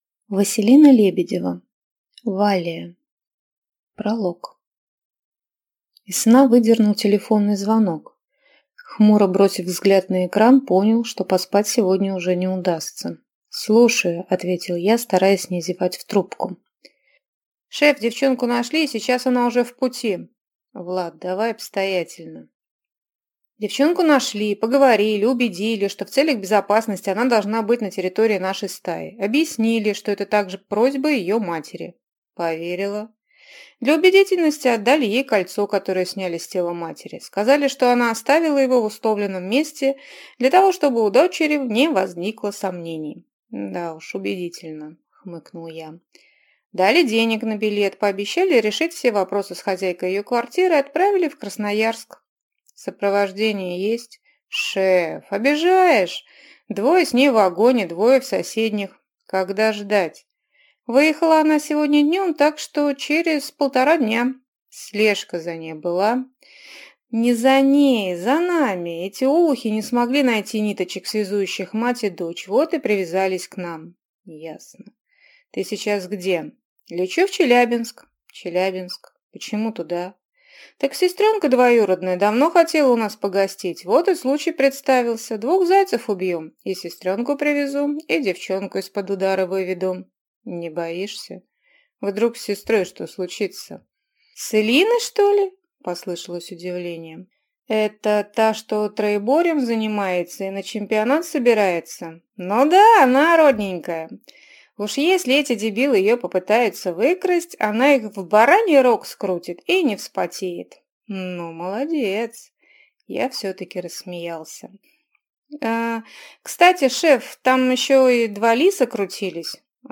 Аудиокнига Валлия | Библиотека аудиокниг